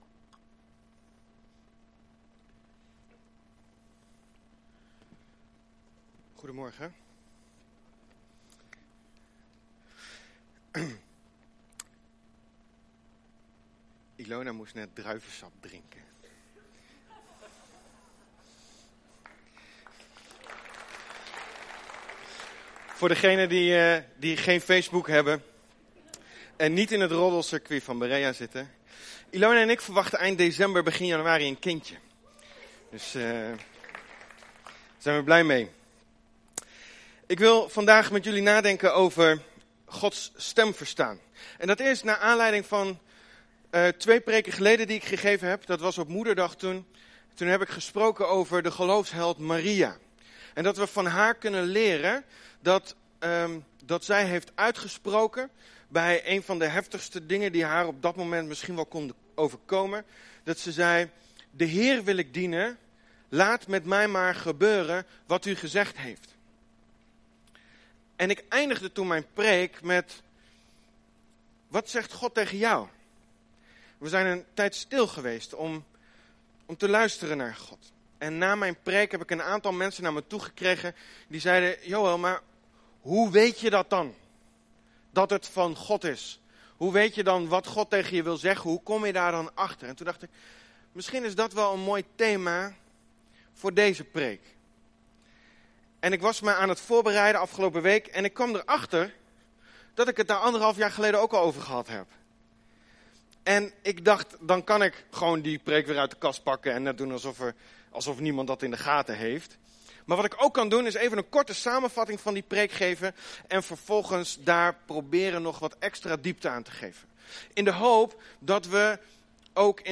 preek
We komen elke zondagmorgen bij elkaar om God te aanbidden.